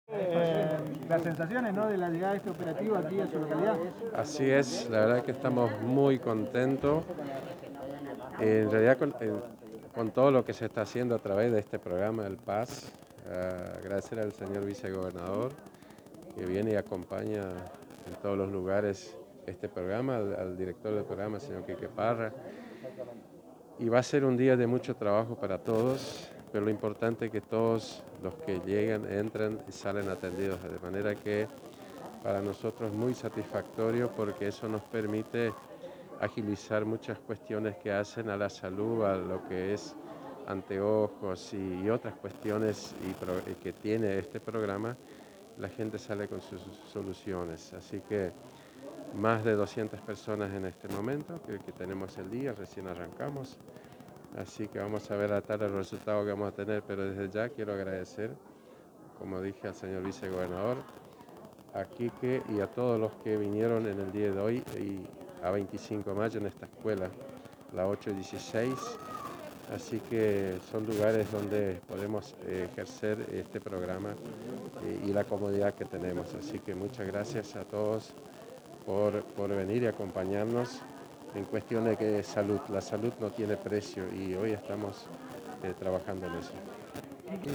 En diálogo con la Agencia de Noticias Guacurarí el intendente Mario Lindemann Expresó,